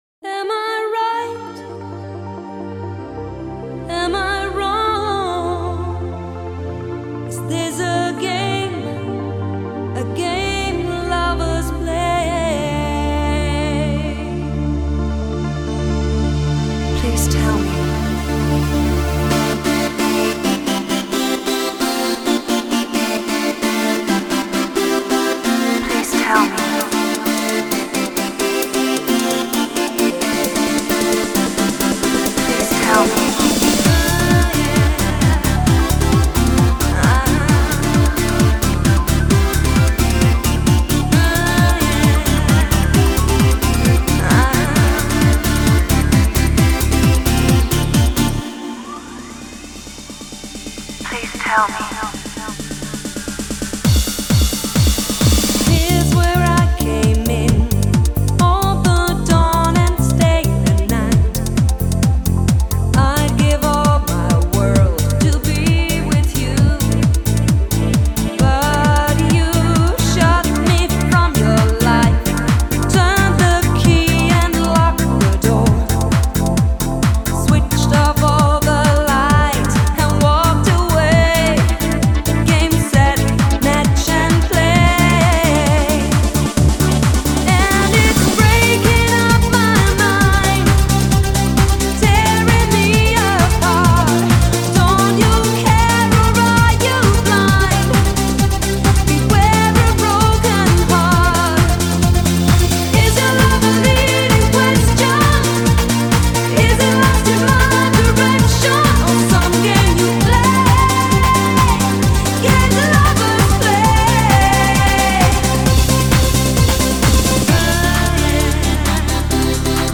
very talented American female soul singer
in the style of Gloria Gaynors “I Will Survive”